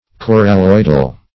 Coralloidal \Cor`al*loid"al\